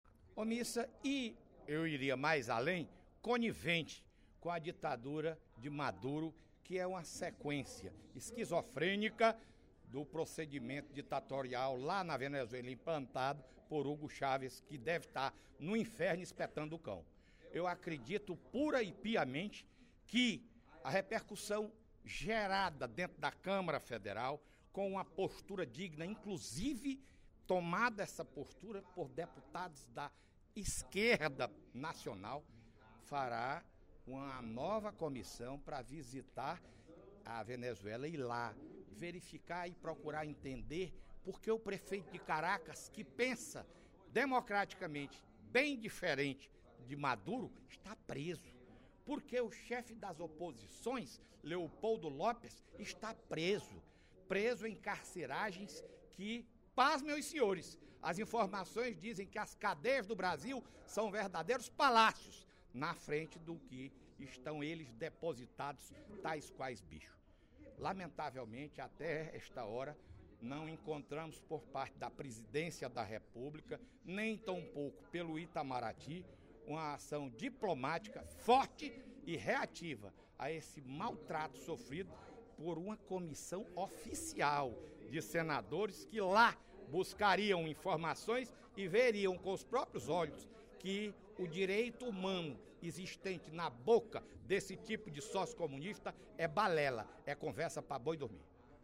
O incidente diplomático envolvendo uma comitiva de senadores brasileiros na Venezuela foi destacado pelo deputado Fernando Hugo (SD), no primeiro expediente da sessão plenária da Assembleia Legislativa desta sexta-feira (19/06).
Em aparte, os deputados Joaquim Noronha (PP), Manoel Duca (Pros), Carlos Felipe (PCdoB), Capitão Wagner (PR), Gony Arruda (PSD) e Heitor Férrer (PDT) também repudiaram o ocorrido.